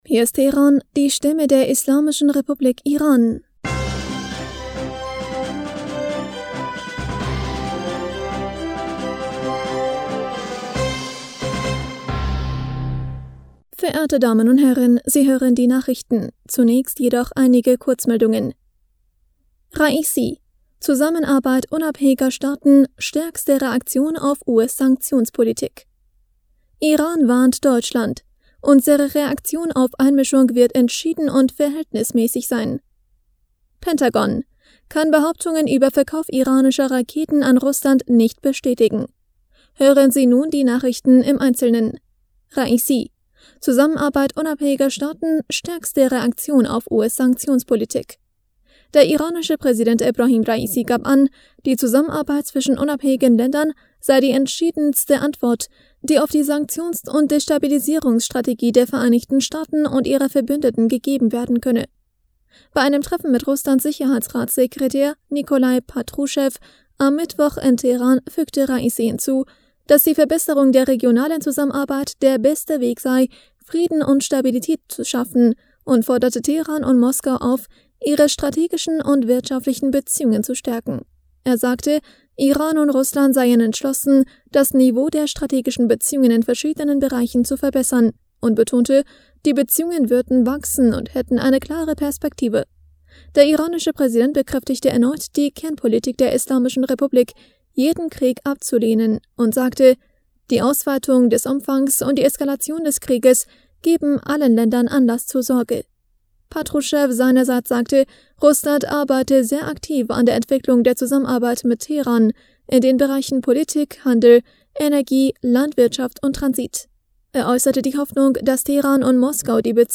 Nachrichten vom 10. November 2022